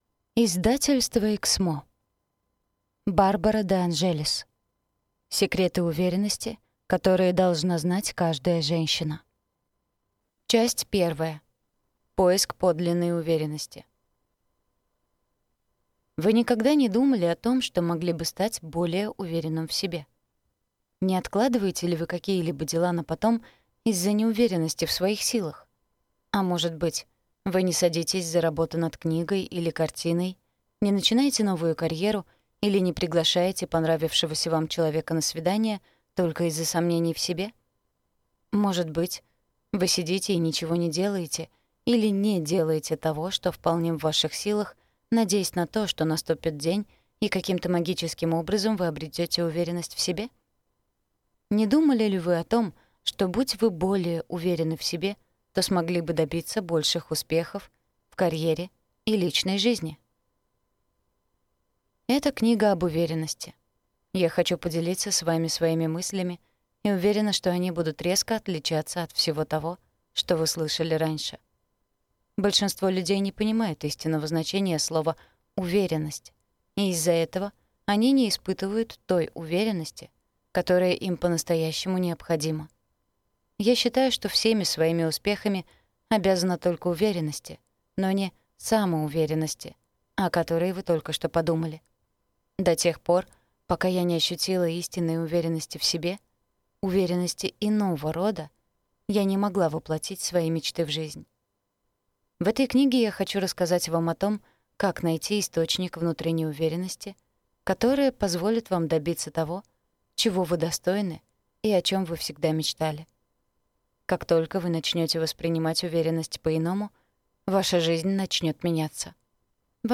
Аудиокнига Секреты уверенности, которые должна знать каждая женщина | Библиотека аудиокниг